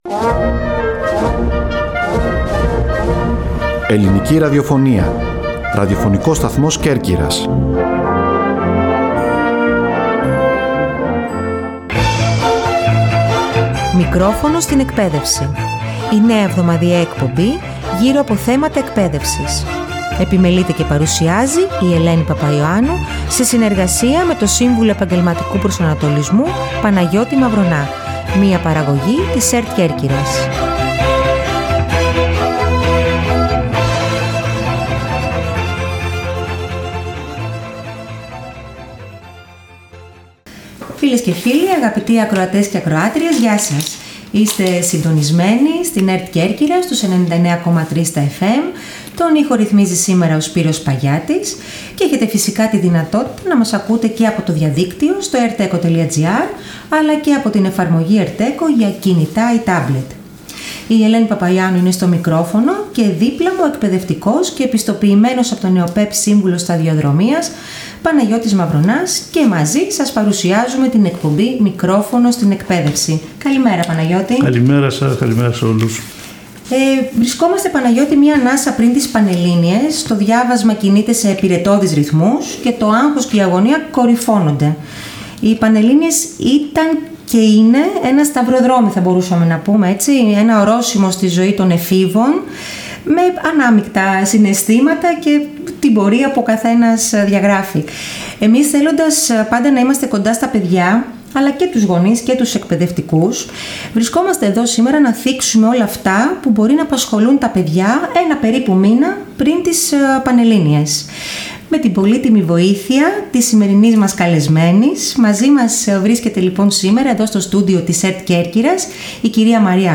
καλεσμένοι στο studio της ΕΡΤ Κέρκυρας